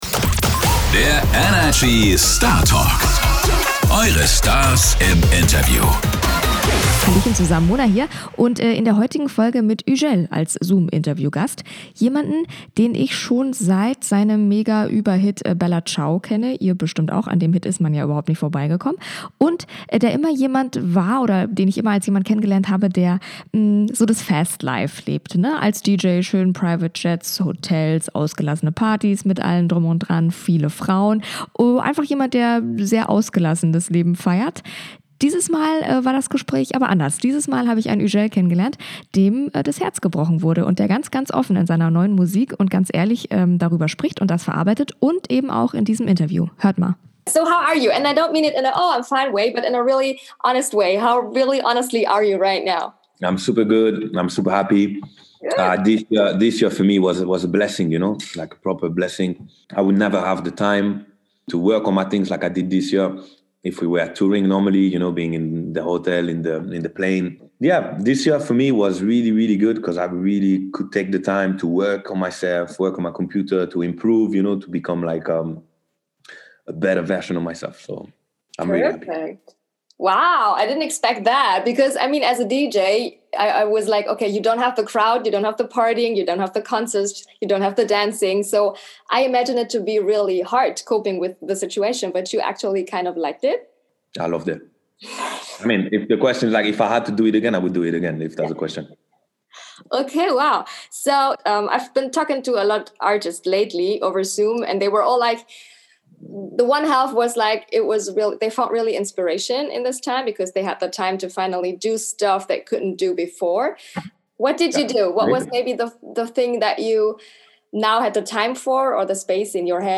Hugel kennen viele vielleicht von seinem Mega-Hit "Bella Ciao" als immer gutgelaunten DJ, mit vielen ausgelassenen Parties, Privatjets und noch mehr Frauen. In diesem Gespräch lernt ihr aber einen Hugel kennen, dem das Herz gebrochen wurde und der das ganz offen in seinen neuen Songs verarbeitet.